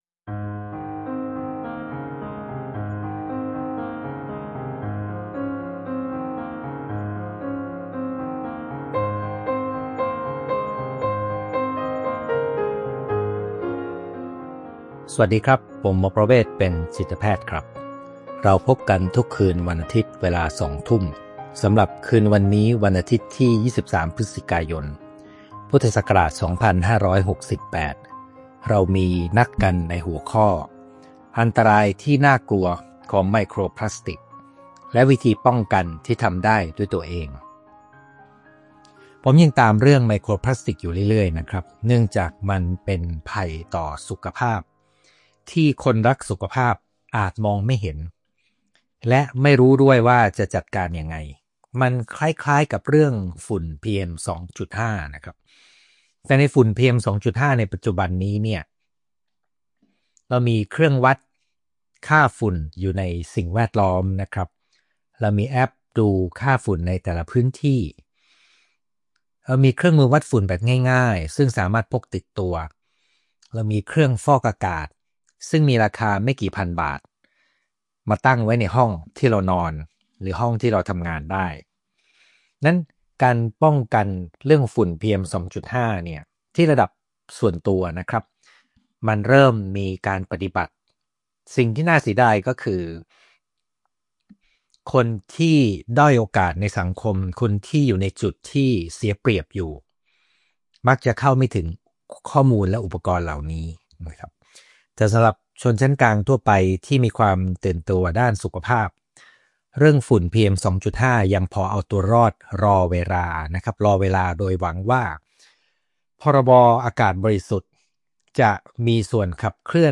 ไลฟ์ประจำวันอาทิตย์ที่ 23 พฤศจิกายน 2568 เวลาสองทุ่ม สน…